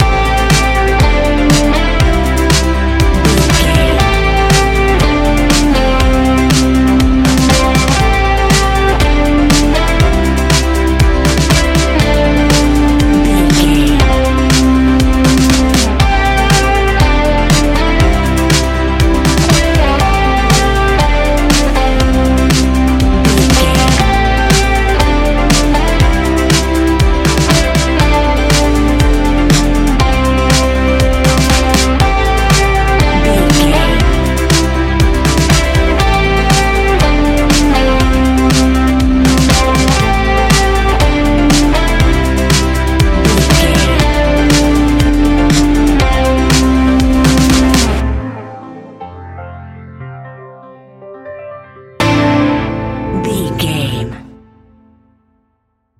Ionian/Major
ambient
electronic
new age
downtempo
pads
drone